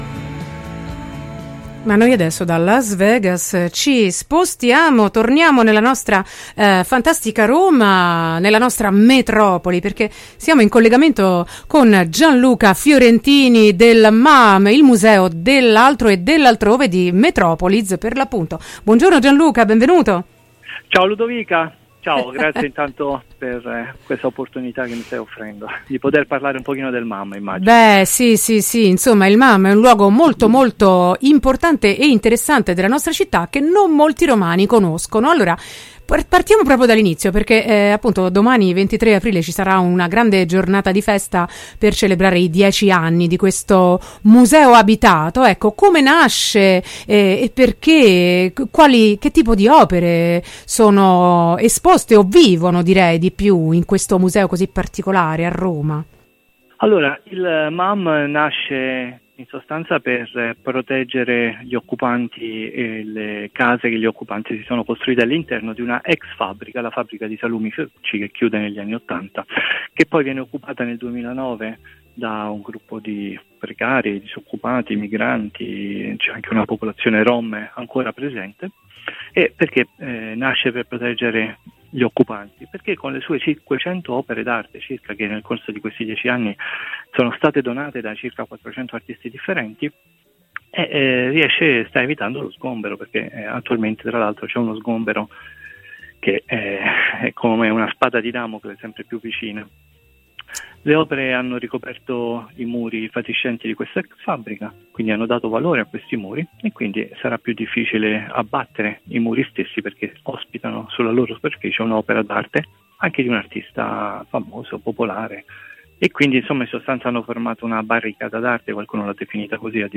intervista-MAAM-22-4-22.mp3